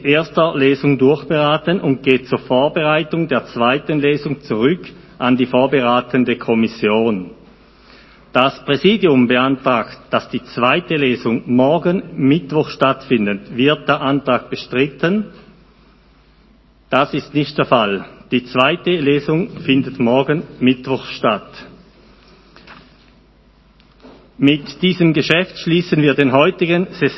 1.12.2020Wortmeldung
Session des Kantonsrates vom 30. November bis 2. Dezember 2020